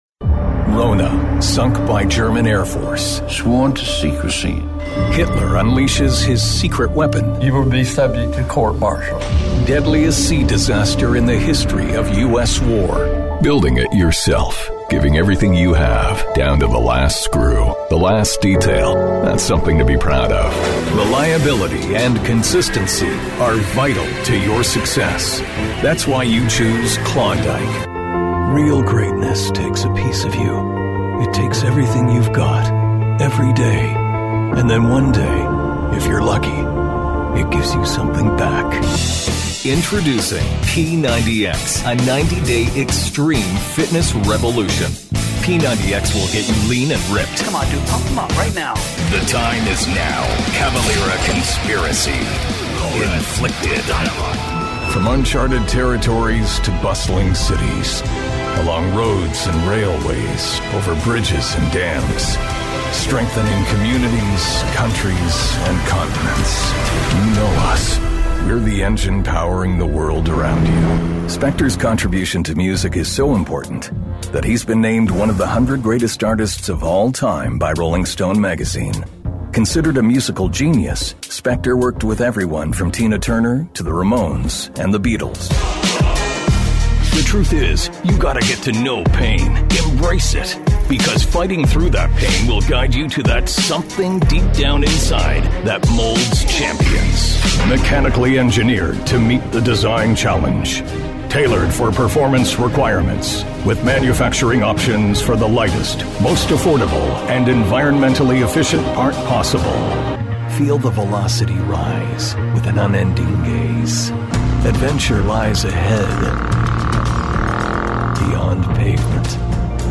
Démo commerciale
Voix masculine « anglais nord-américain neutre » (sans accent régional).
Microphone Neumann TLM103